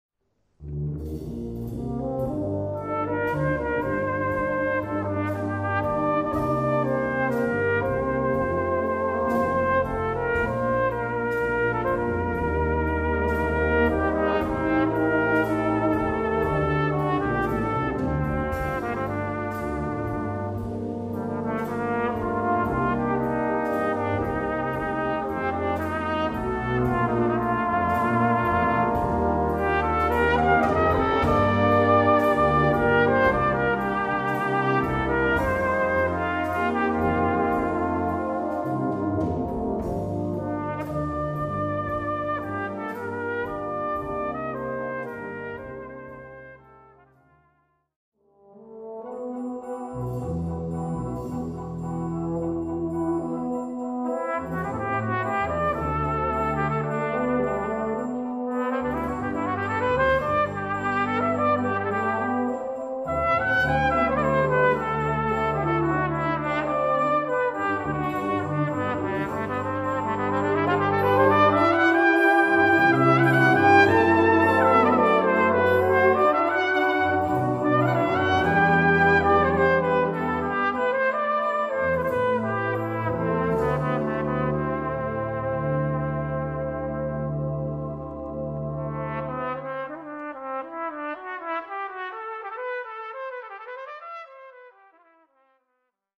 Cornet et Brass Band